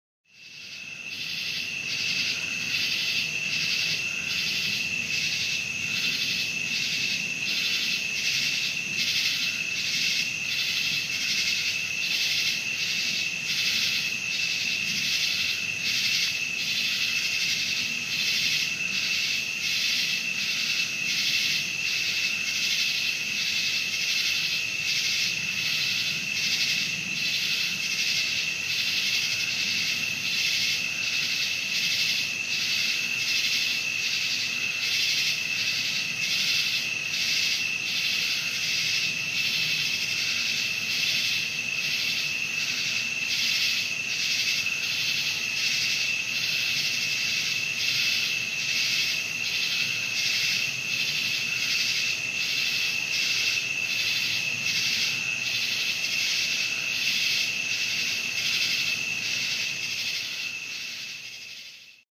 I love the sounds of a late summer evening — they remind me that there’s so much more to Mother Nature than the obvious.
nightsoundsb.mp3